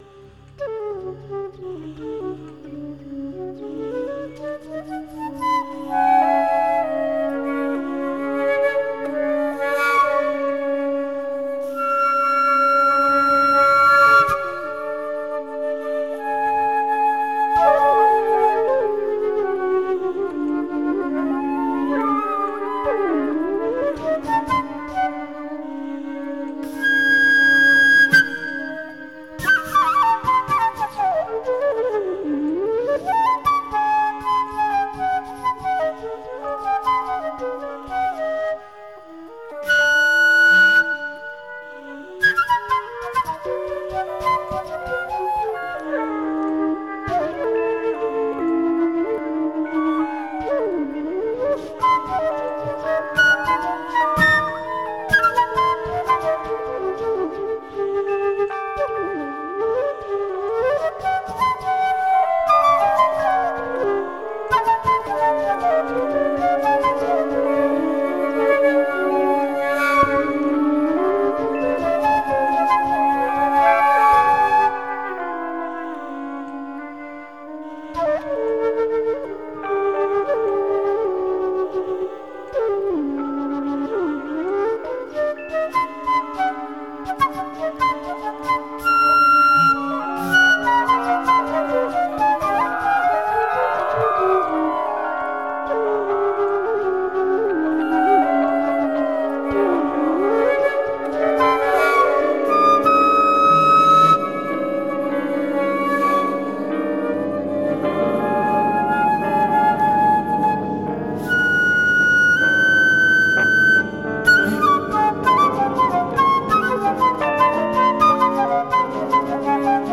field recordings, mixing, processing
flute
piano fender rhodes